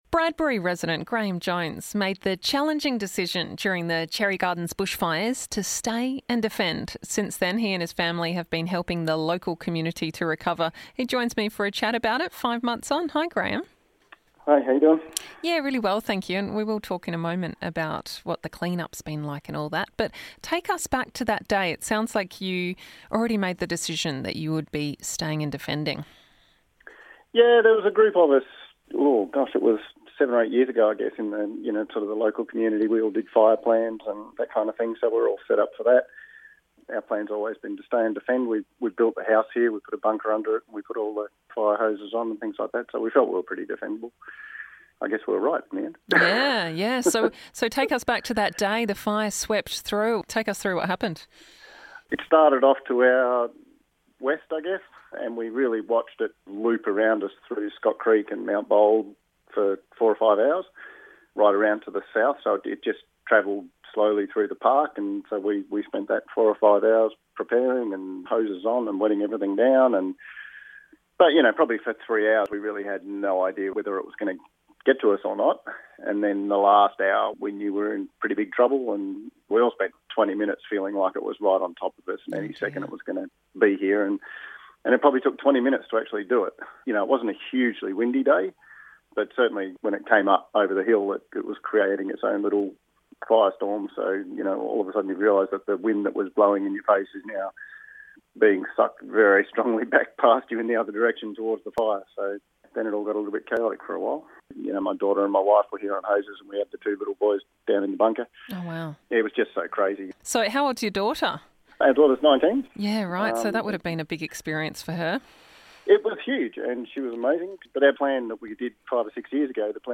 for a chat five months on.